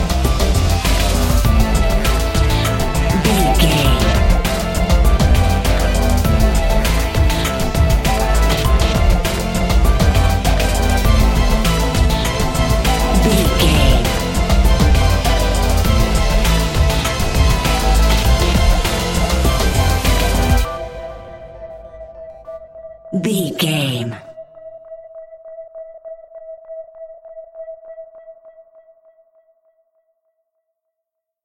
Fast paced
In-crescendo
Aeolian/Minor
strings
synth effects
driving drum beat